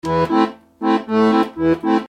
Melodeon.mp3